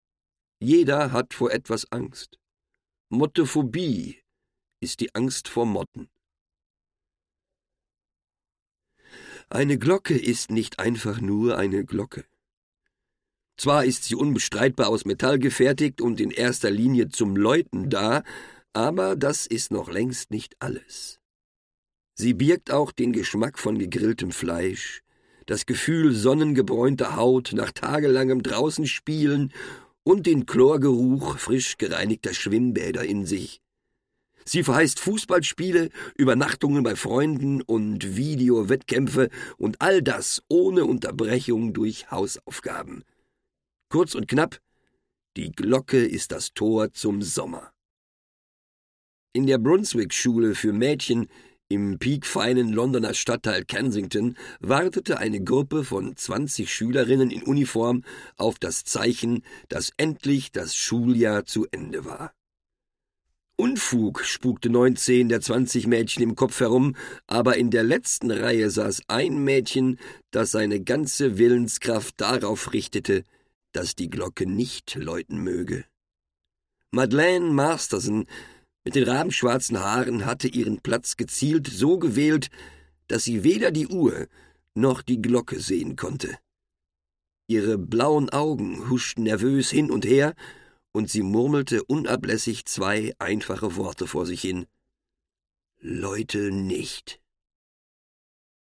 sehr variabel, dunkel, sonor, souverän
Mittel plus (35-65)
Audiobook (Hörbuch)